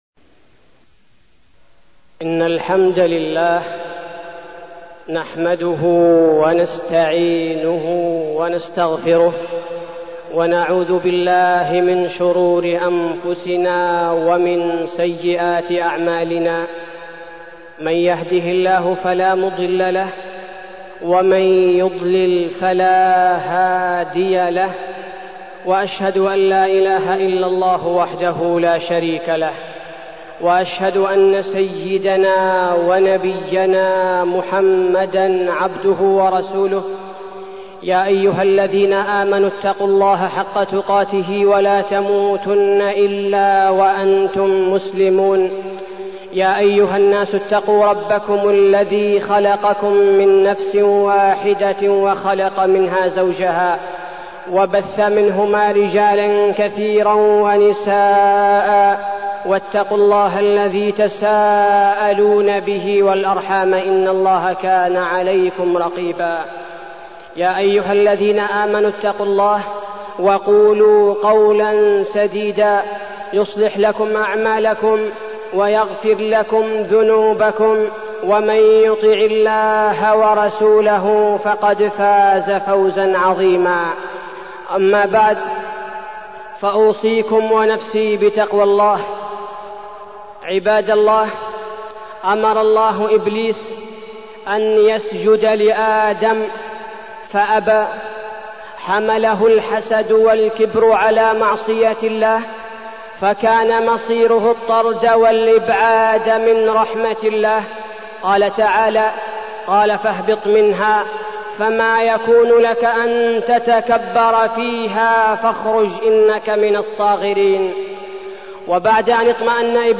تاريخ النشر ١٥ ربيع الثاني ١٤١٩ هـ المكان: المسجد النبوي الشيخ: فضيلة الشيخ عبدالباري الثبيتي فضيلة الشيخ عبدالباري الثبيتي الشيطان